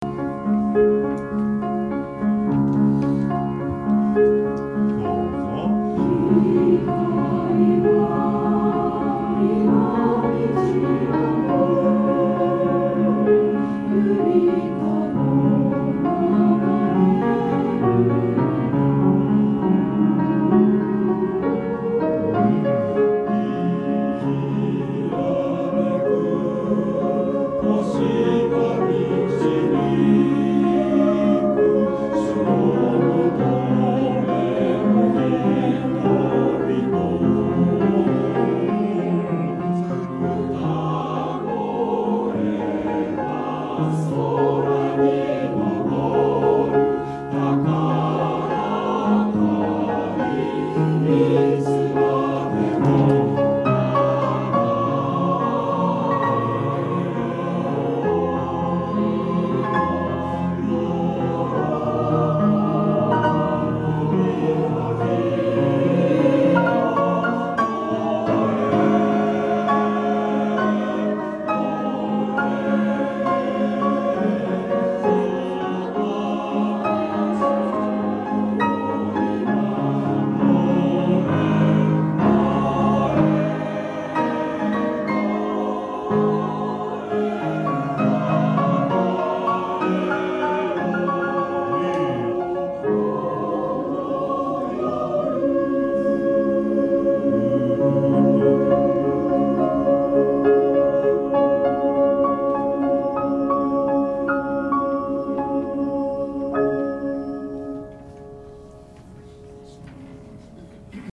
練習場所：アスピア明石北館　8階学習室801A・B（明石市）
出席者：31名（sop13、alt8、ten5、bass5）